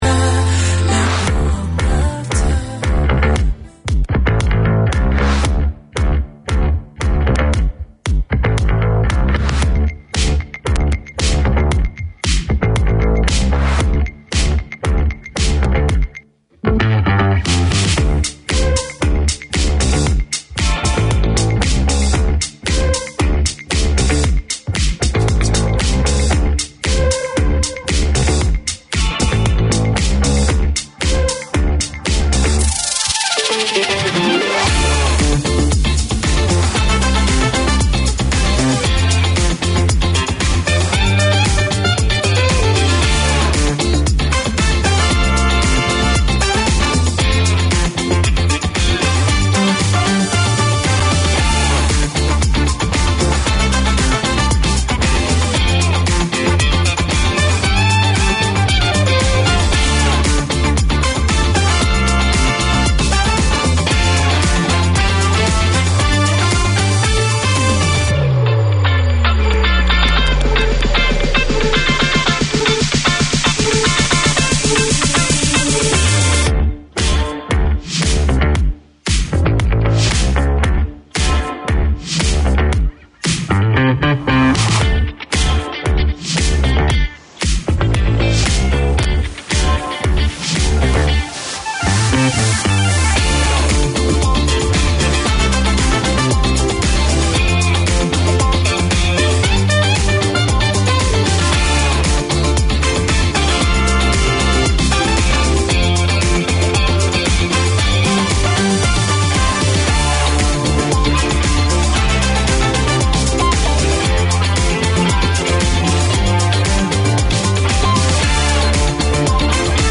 From local legends in grassroots to national level names, the Sports Weekender features interviews, updates and 'the week that was'.